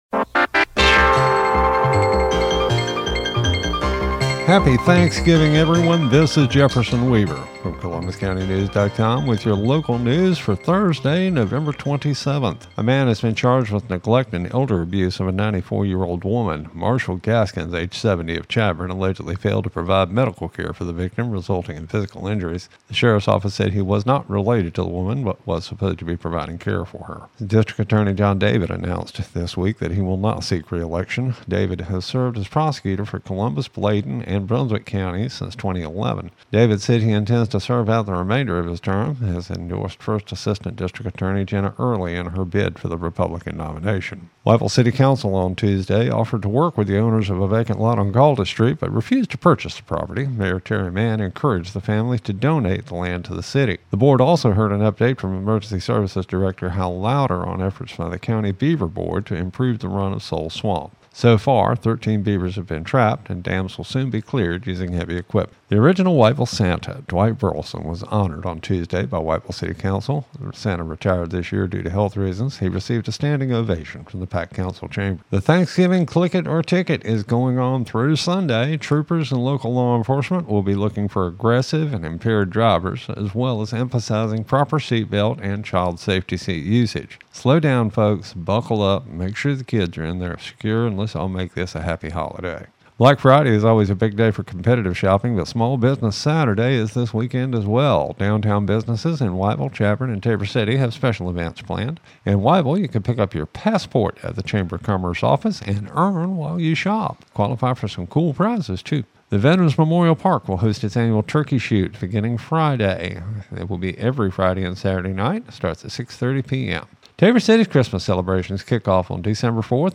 CCN Radio News — Thanksgiving Morning Report for November 27, 2025